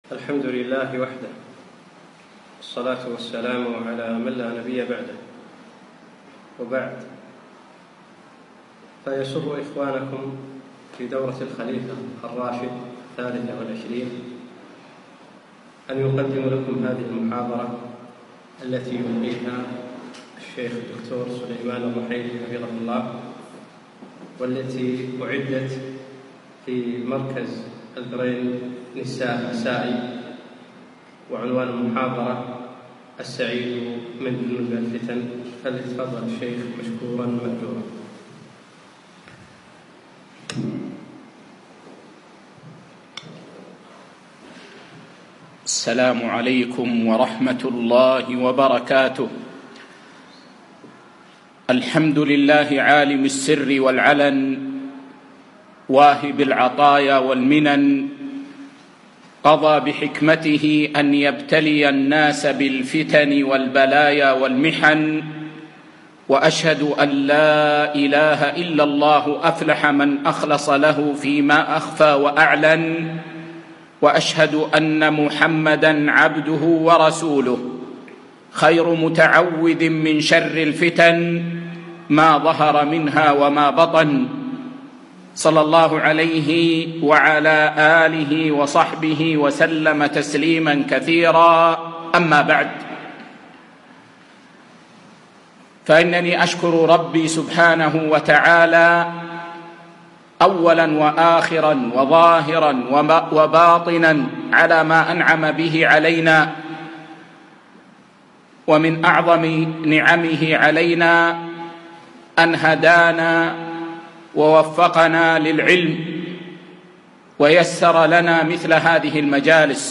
محاضرة - السعيد من جنب الفتن